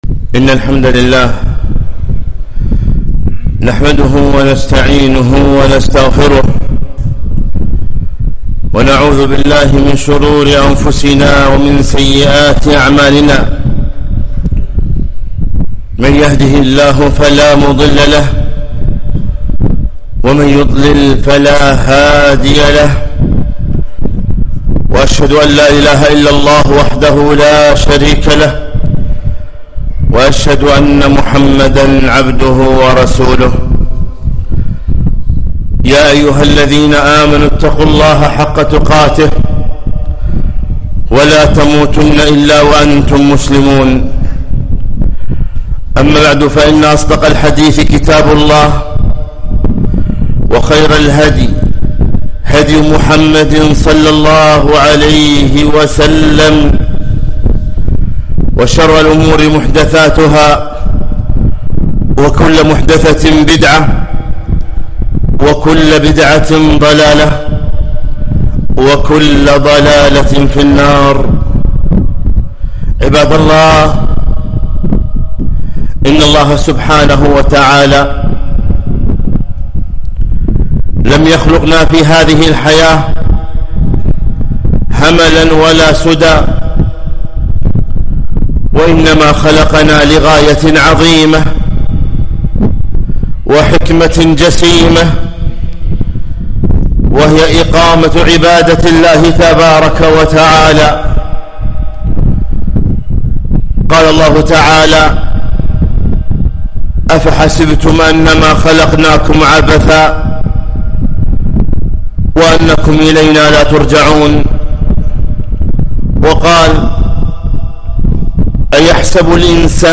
خطبة - (اقرأ كتابك كفى بنفسك اليوم عليك حسيبا)